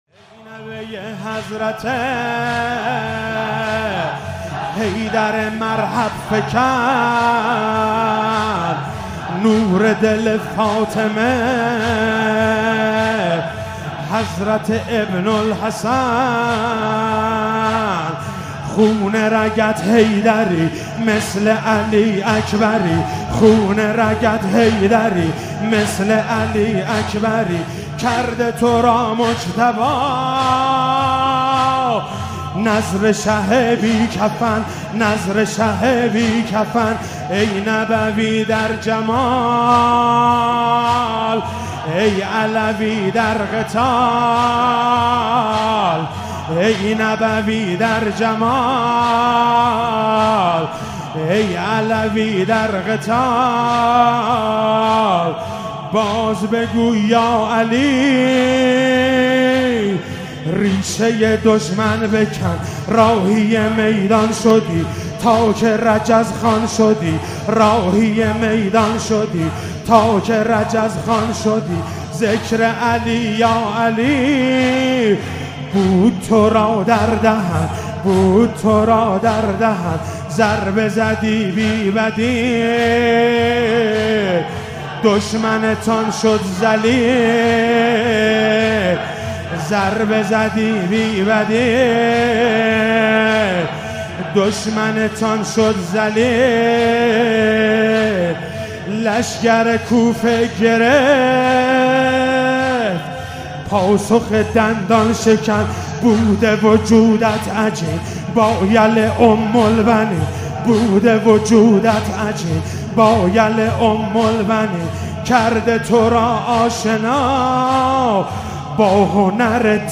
شب ششم محرم96 - زمینه - ای نوه حضرت حیدر